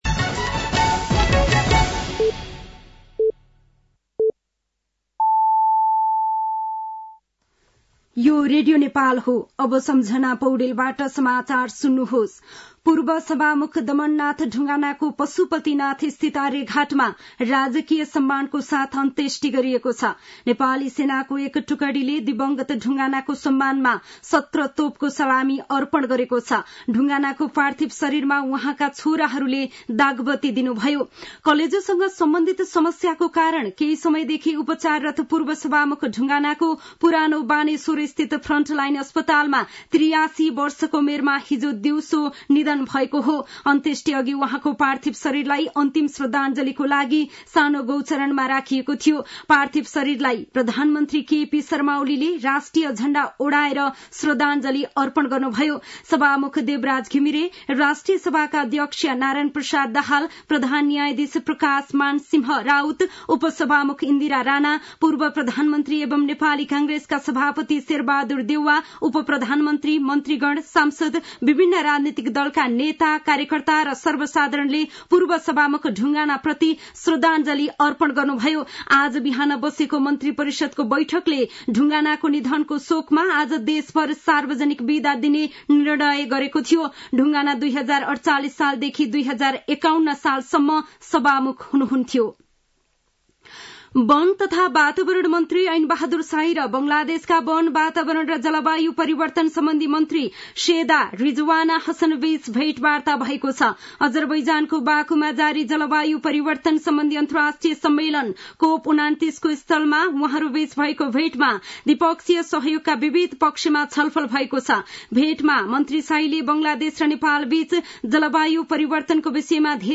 साँझ ५ बजेको नेपाली समाचार : ४ मंसिर , २०८१
5-pm-nepali-news-8-02.mp3